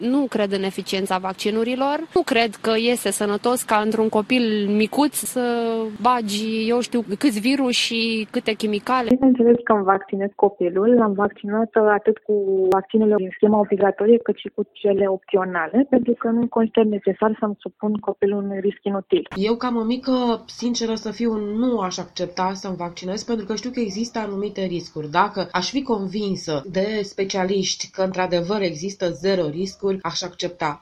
mamici-vaccinare.mp3